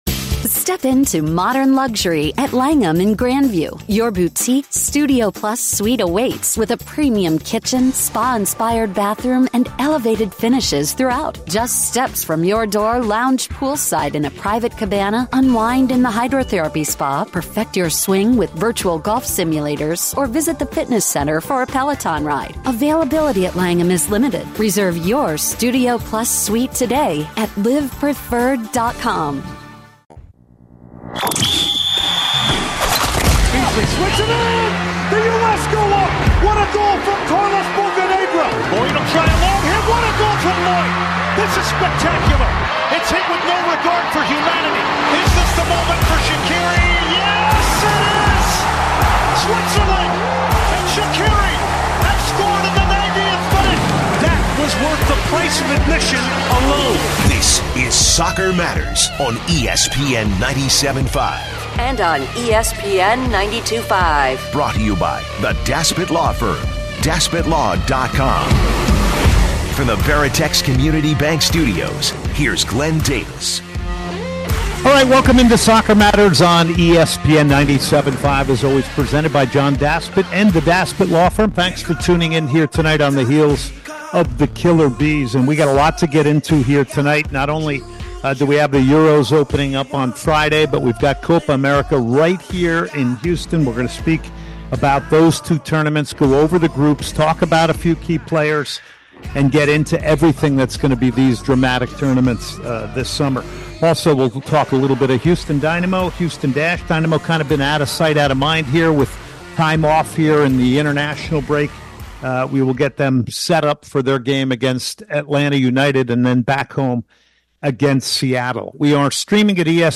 Tab Ramos joins to have a conversation regarding the state and future of the USMNT.